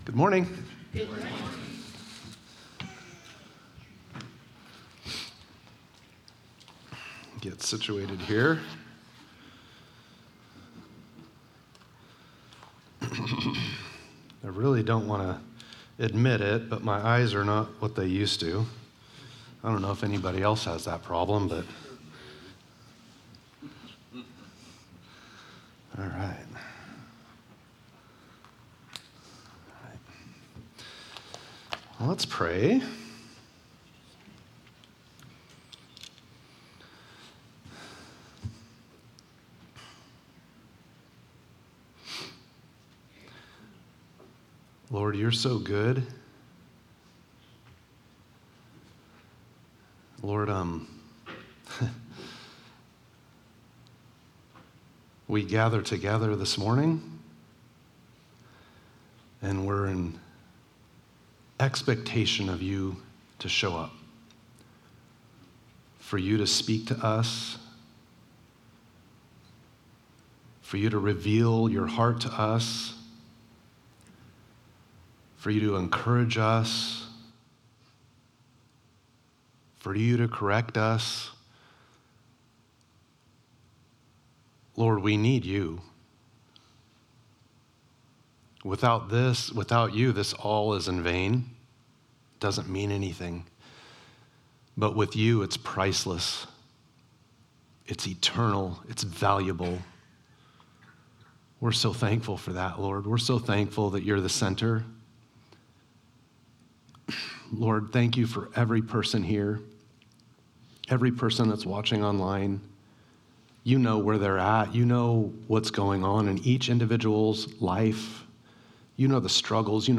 Living in the Waiting Service Type: Sunday Morning « Living in the Waiting